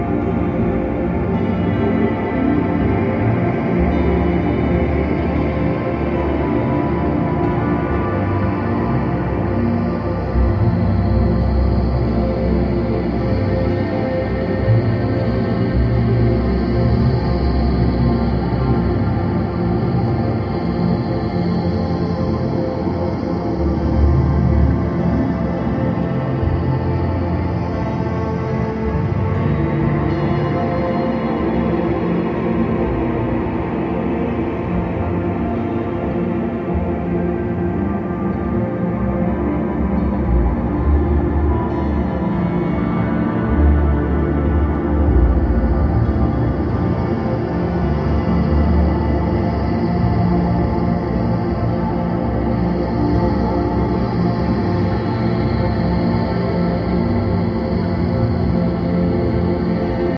壮大なサウンドスケープは、忙しない日常の心の支えとなるメディテーションに没入できます。